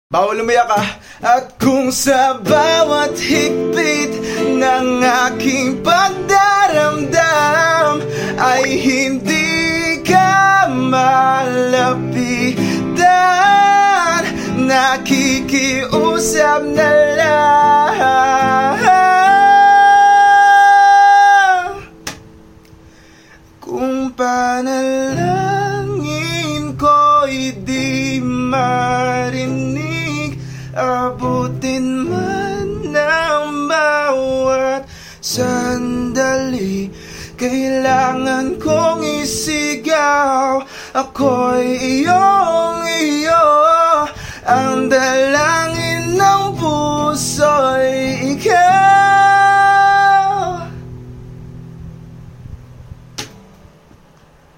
Portable Nose Hair Trimmer Electric sound effects free download